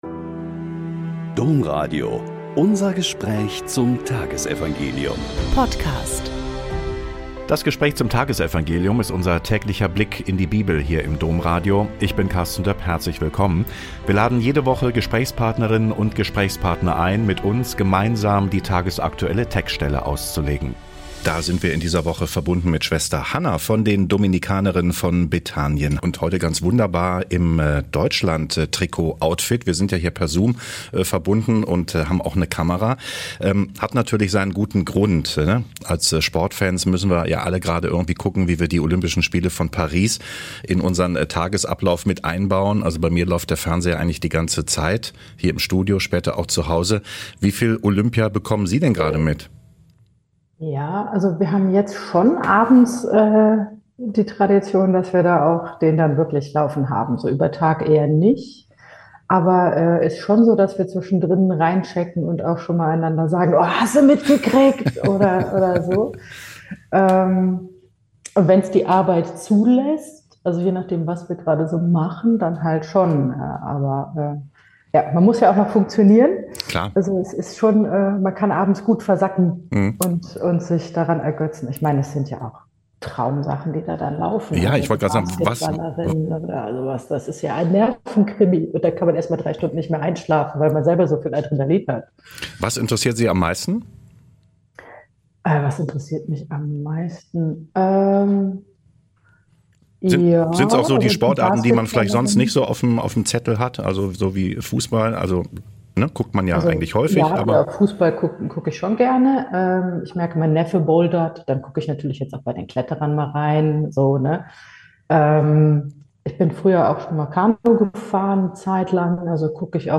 Mt 15,21-28 - Gespräch